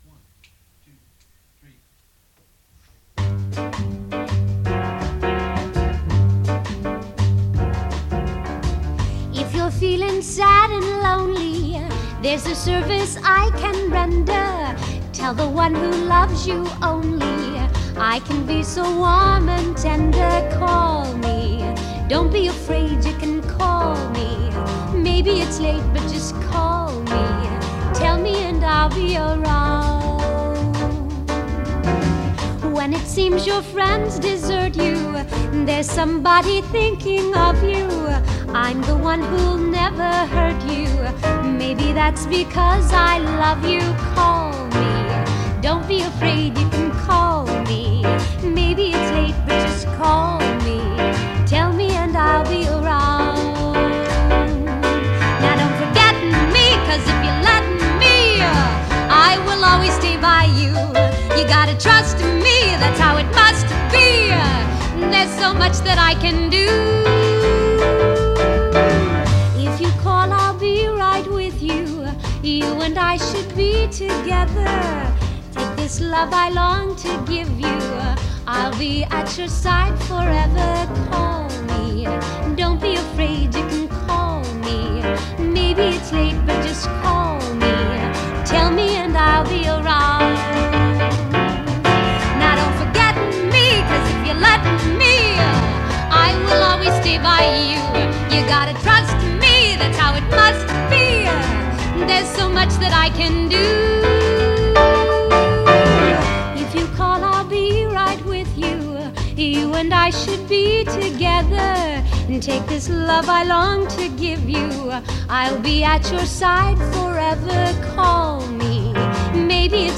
She’s very young and bubbly.